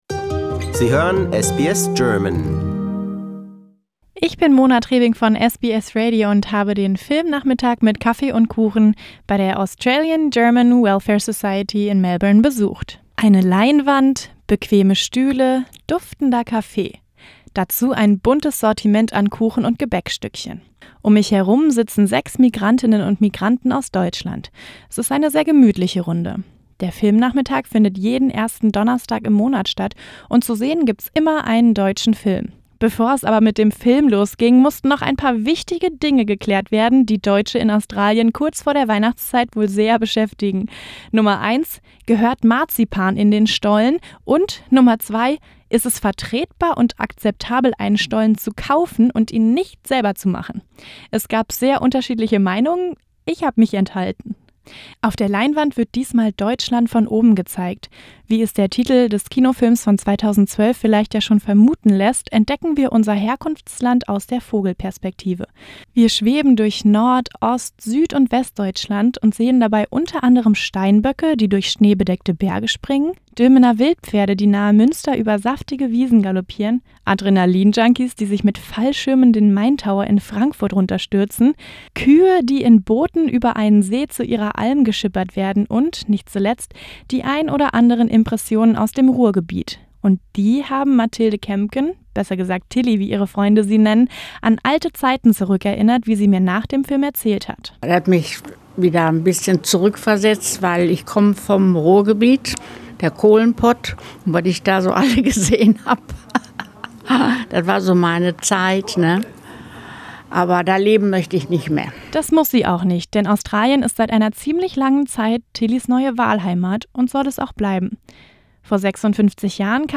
Filmnachmittag bei Australian-German Welfare Society